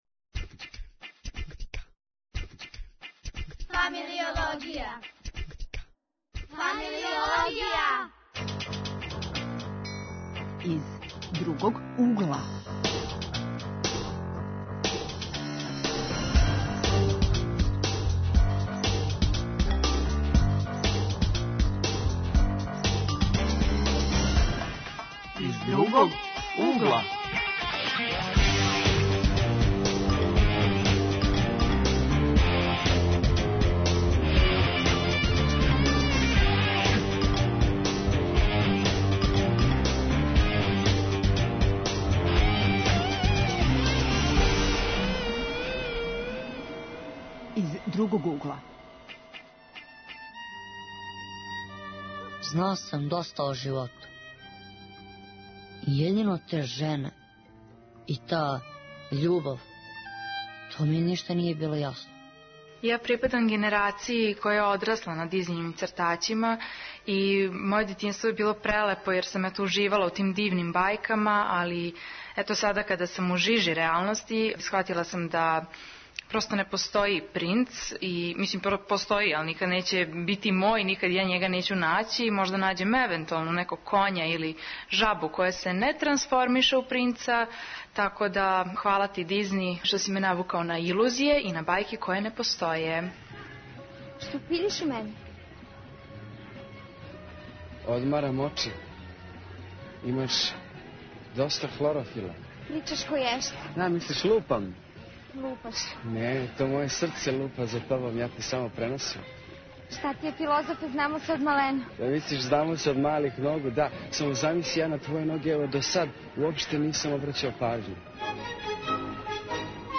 Гости у студију су